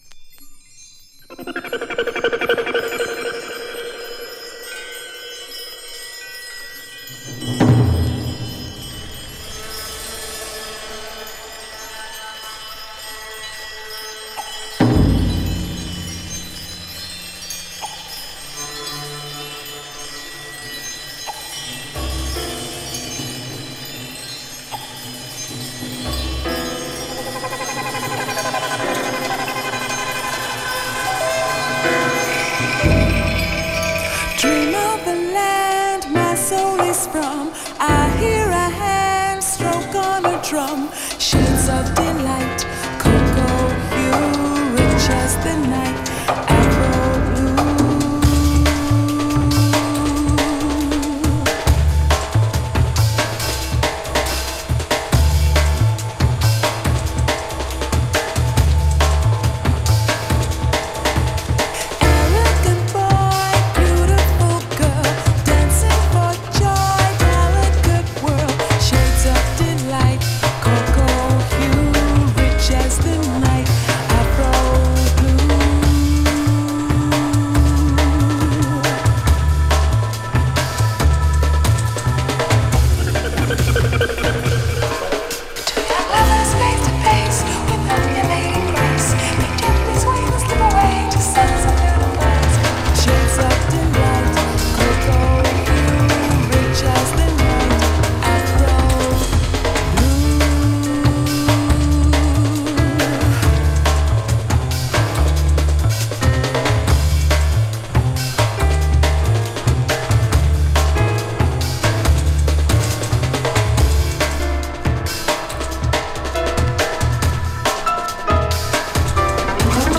エッジの効いたブロークンビーツも格好良い、クオリティも申し分ない仕上がりで、根強い人気のバージョンです。